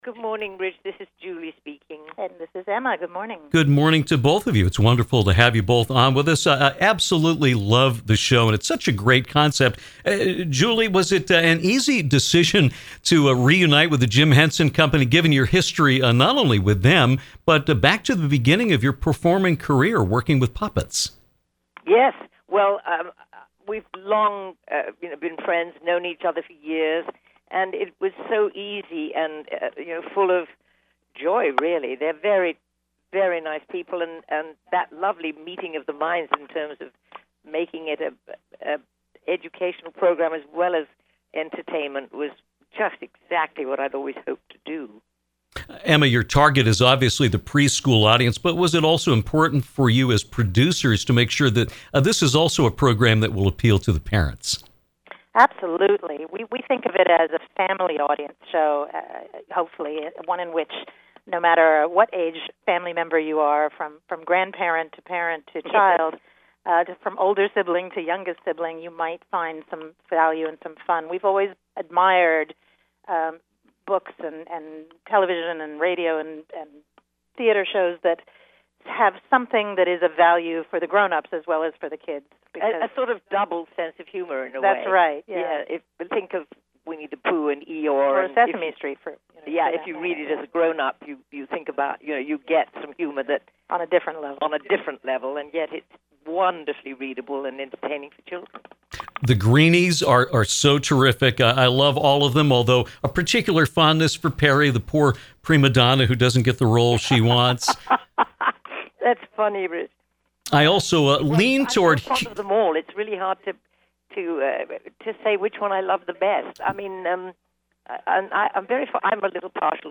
A special treat for Downtown as Julie Andrews and her daughter Emma Walton Hamilton joined us to talk about their wonderful children’s show, “Julie’s Greenroom”, which is available on Netflix. They discussed reuniting with The Jim Henson Company, meeting today’s young viewers where they are, and the importance of arts education.